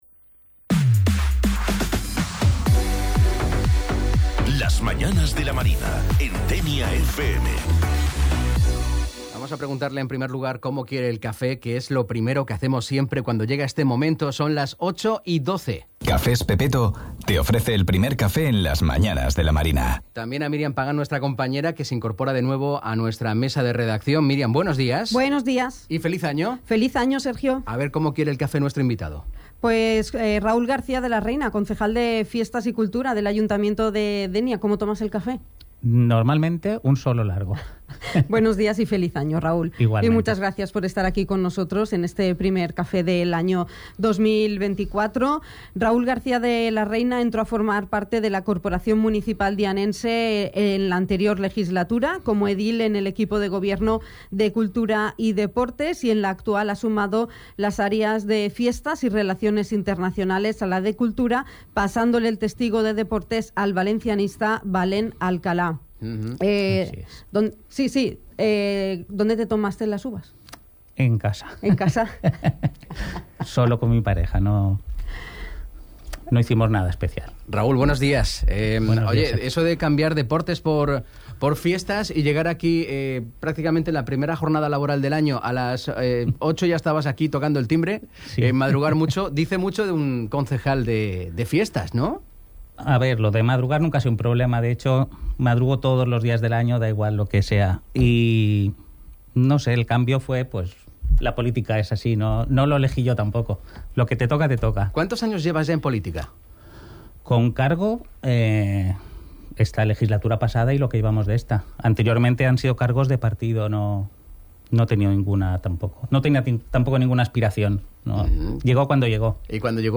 Entrevista-Raul-Garcia-de-la-Reina.mp3